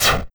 Fireball.wav